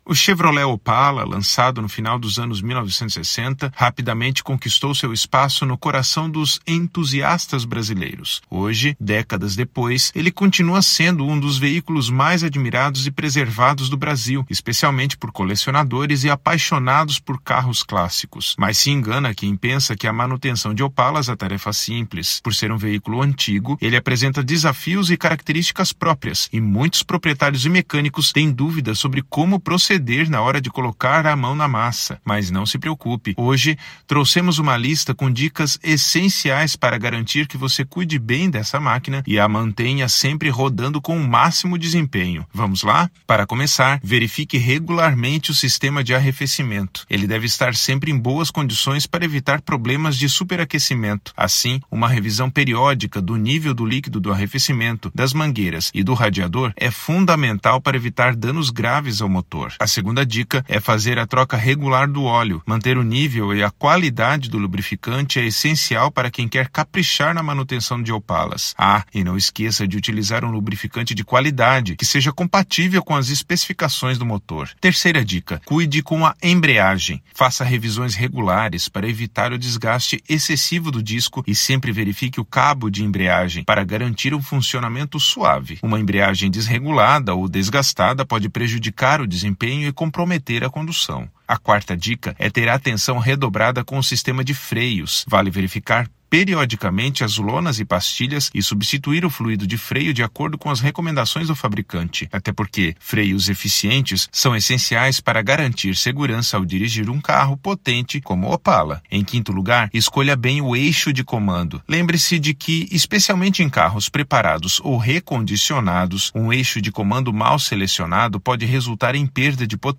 Narracao-03-manutencao-de-opalas_1.mp3